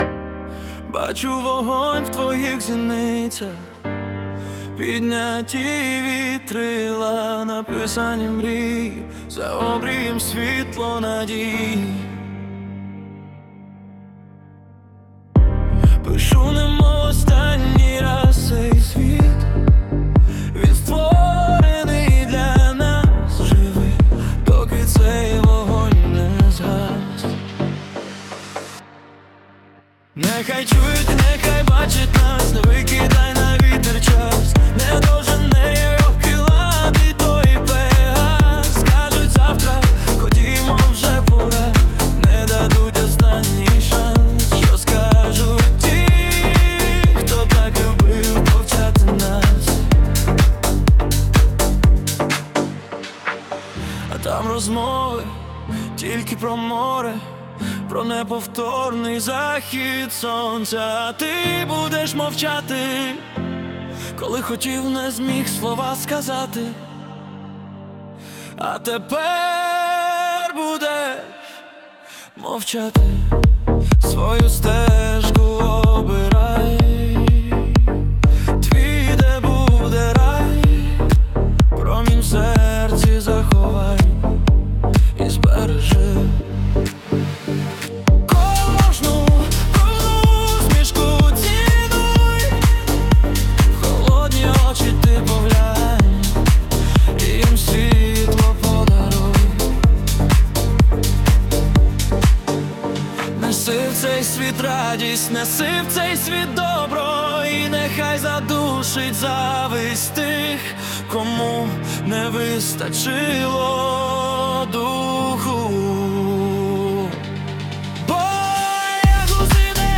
Егоїст серед вас (+🎧музична версія)